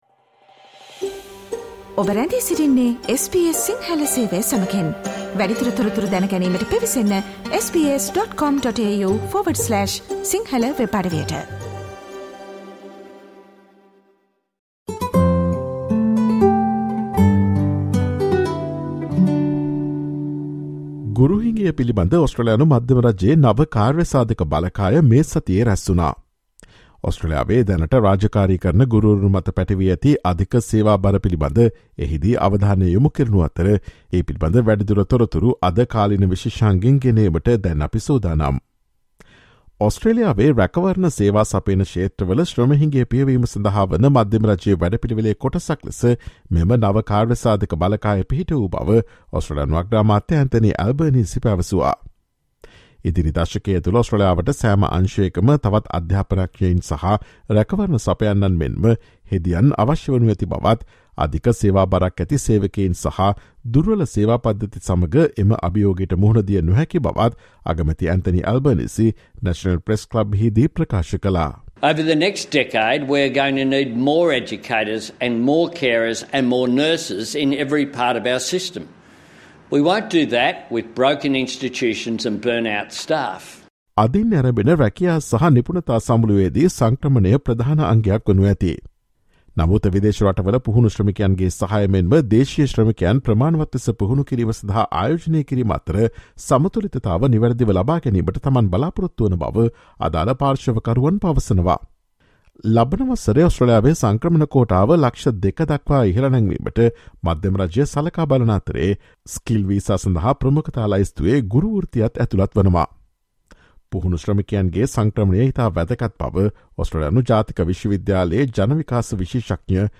Listen to SBS Sinhala Radio's current affairs feature broadcast on Thursday, 01 September with the latest information on teachers demanding solutions from Australian federal government to the existing serious workforce shortage.